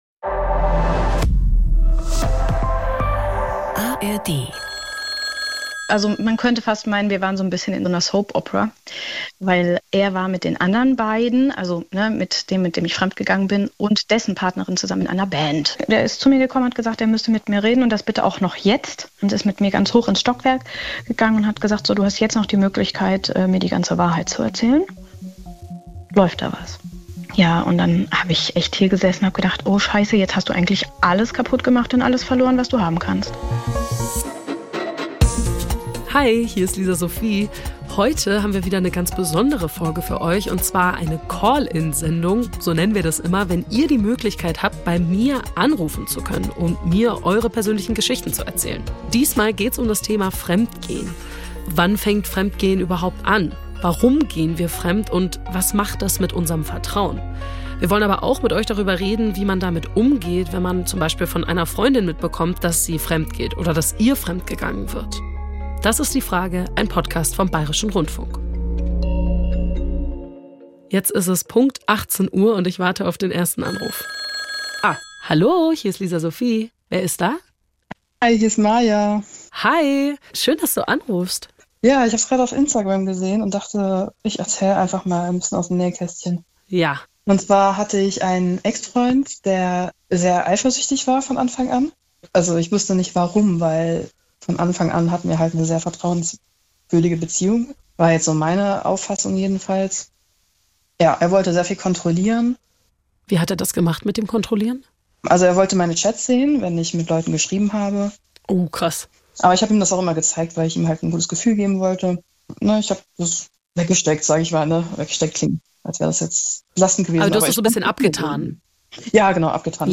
Ich will euch mitnehmen - in Gespräche, die etwas in uns auslösen, die verändern, wie wir andere wahrnehmen oder auf diese Welt schauen.